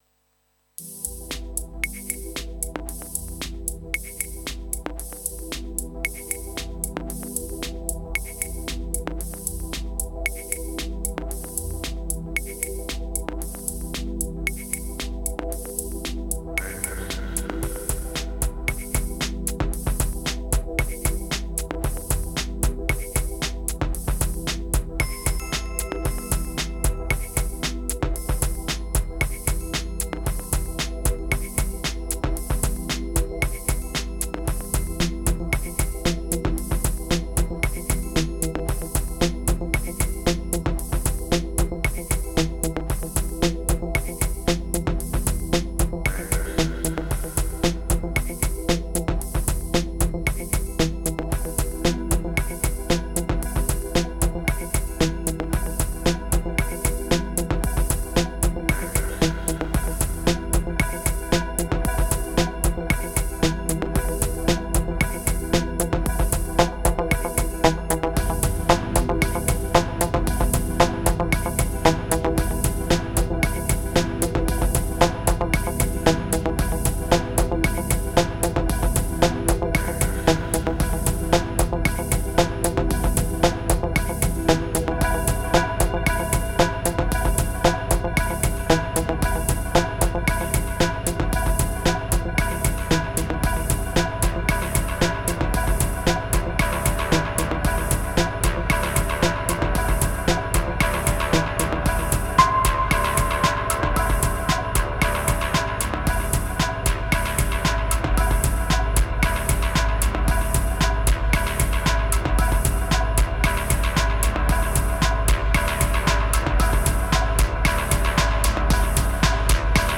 500📈 - 76%🤔 - 114BPM🔊 - 2022-03-13📅 - 413🌟